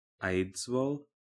Eidsvoll (pronounced [ˈæ̀ɪdsvɔɫ]
Eidsvold.ogg.mp3